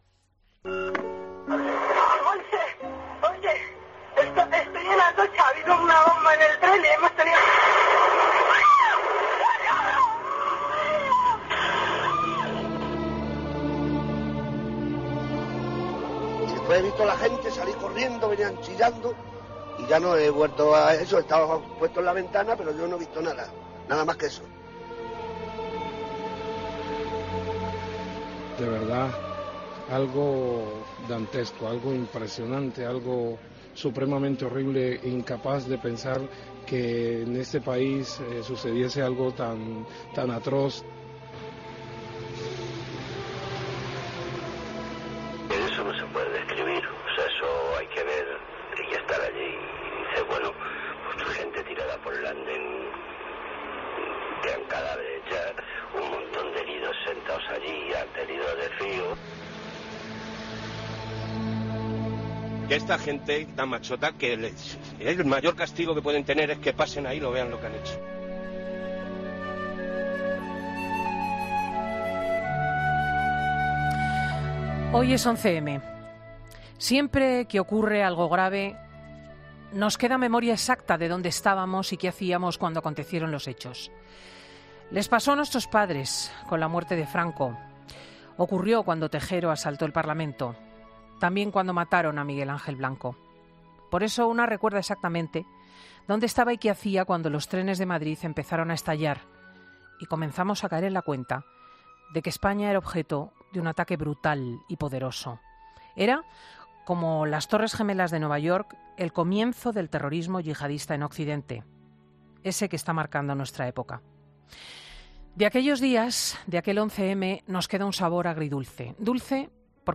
Monólogo de Cristina López Schlichting
El comentario de Cristina L. Schlichting